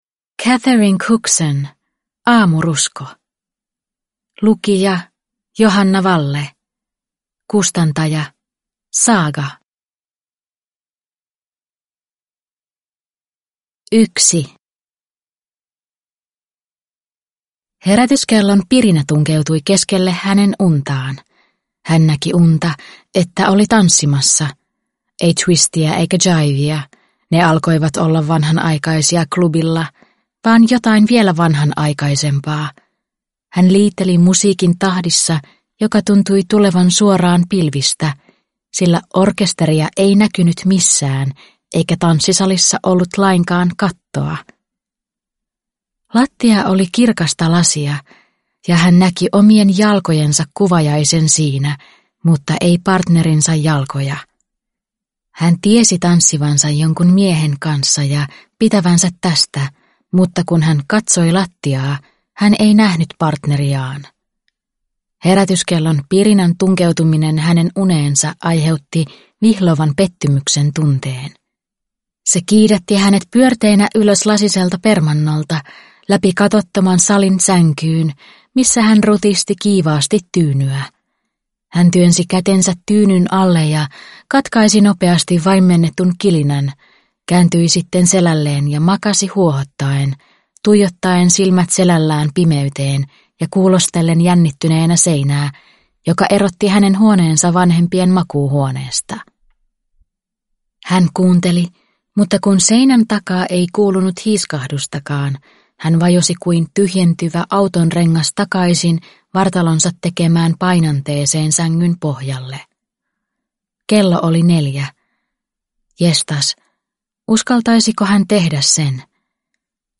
Aamurusko (ljudbok) av Catherine Cookson